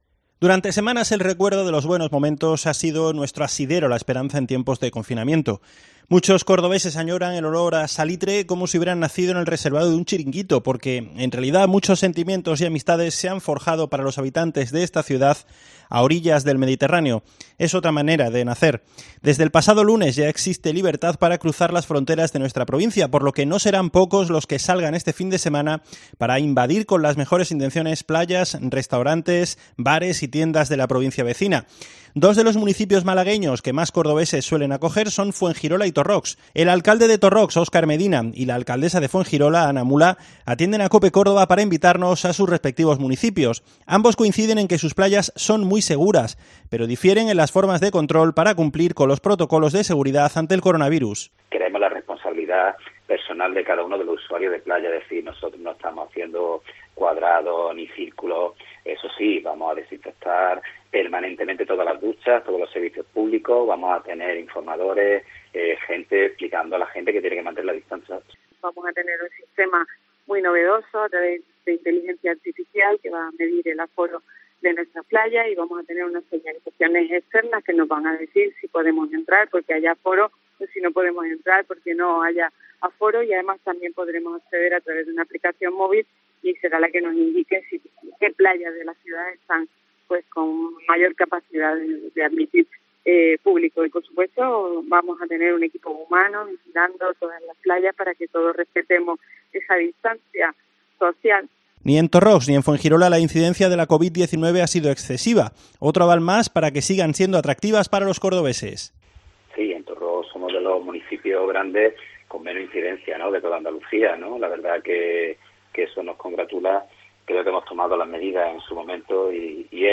El alcalde de Torrox, Óscar Medina (PP) y la alcaldesa de Fuengirola, Ana Mula (PP) atienden a COPE Córdoba para invitarnos a sus respectivos municipios.